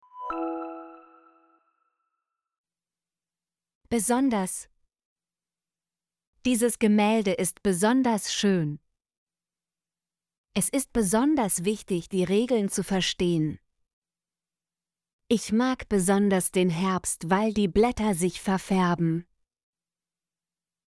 besonders - Example Sentences & Pronunciation, German Frequency List